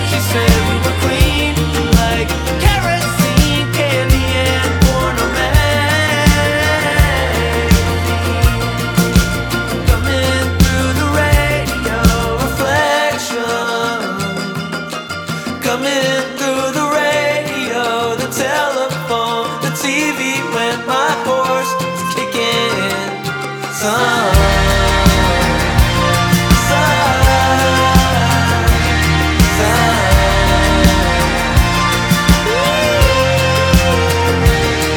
Скачать припев
2025-05-29 Жанр: Альтернатива Длительность